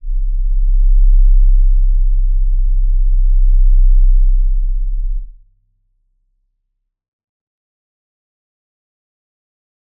G_Crystal-C1-pp.wav